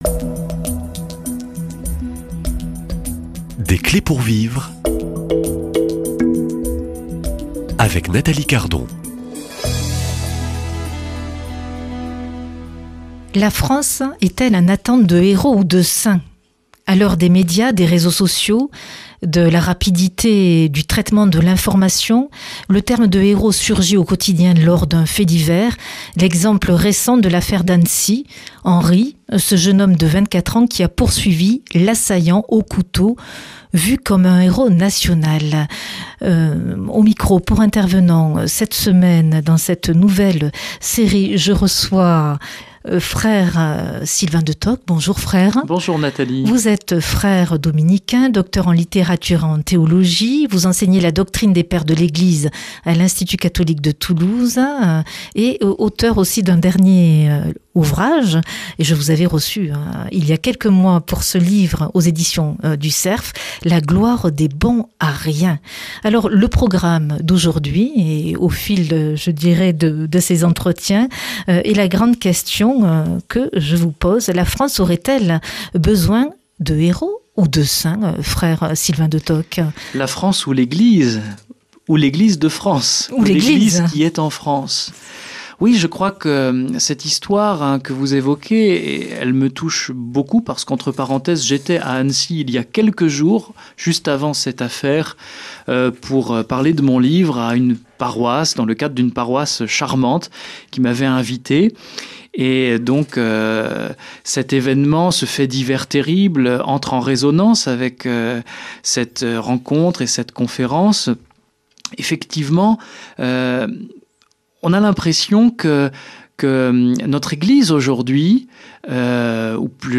Quels sont les points communs entre les héros et les saints ? Quelles sont leurs différences ? Invité : Prêtre, dominicain, docteur en littérature et en théologie